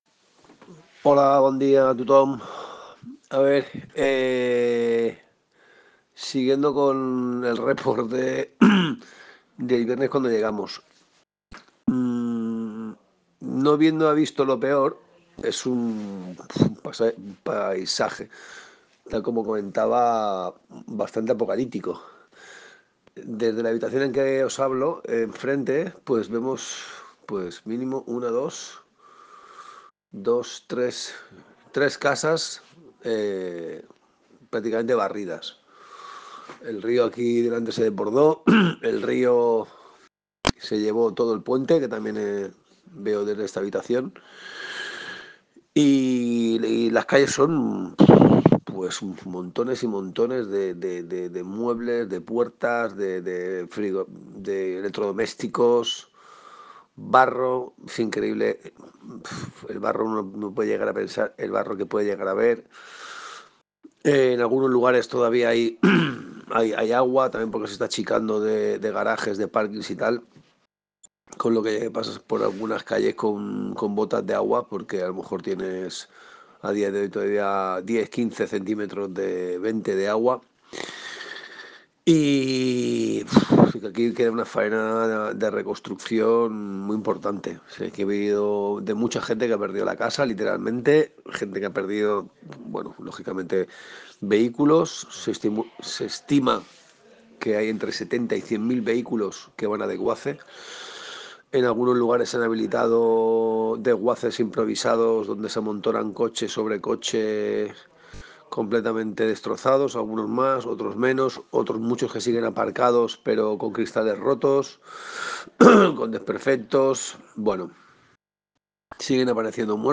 Nos hacen llegar, una crónica desde Valencia. Por la urgencia la colgamos tal cual.